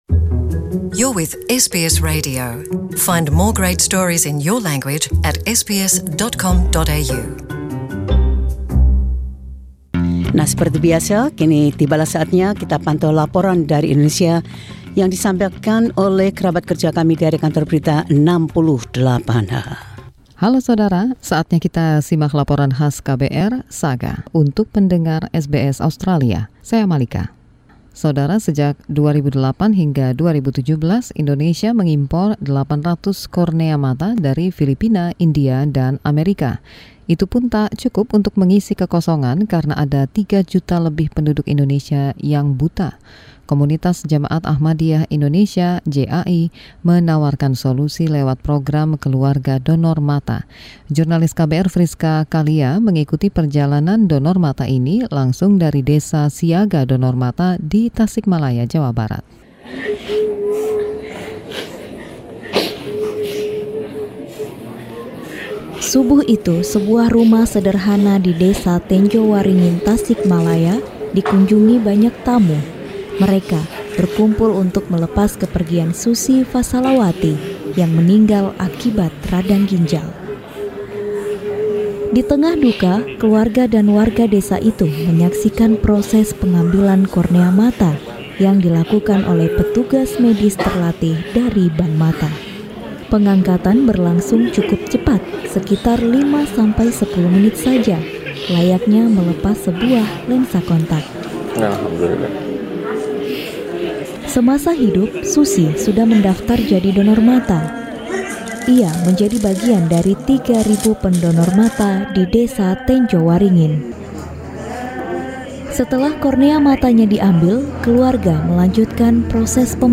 The team from KBR 68H report on the humanitarian spirit of the people of Tenjowaringin.